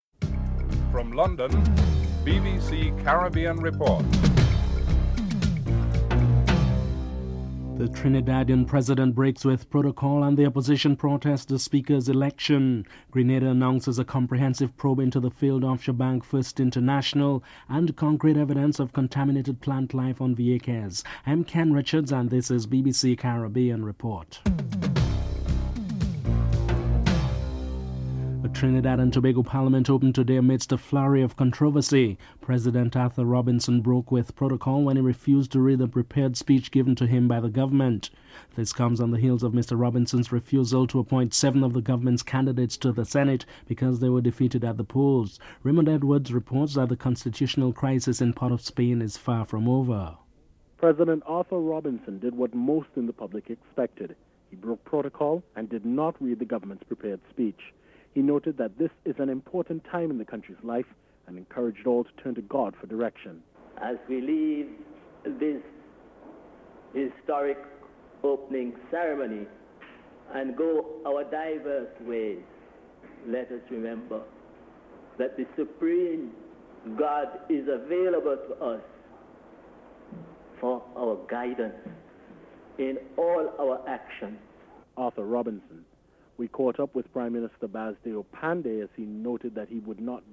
1. Headlines (00:00-00:27)
4. Dominica's rush into relations with Libya may have fractured a proposed united OECS approach to such relations. Prime Minister Keith Mitchell is interviewed (08:08-10:53)